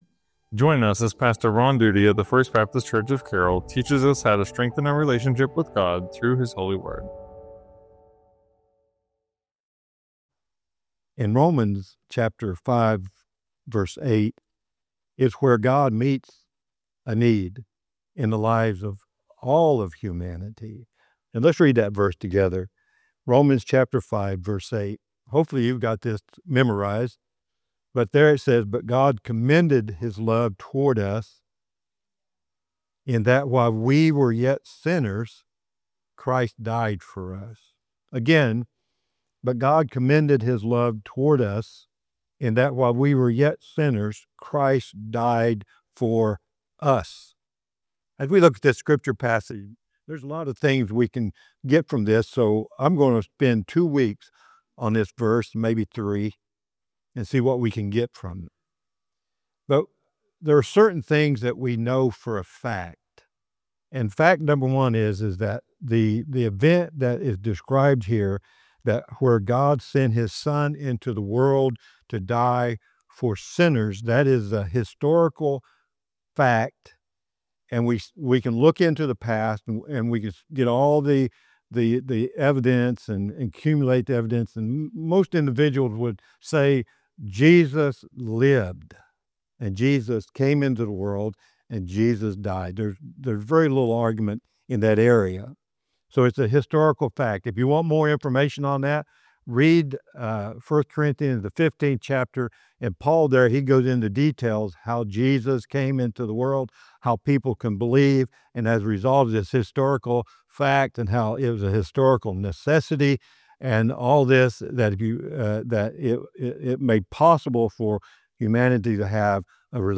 Sermons | First Baptist Church of Carroll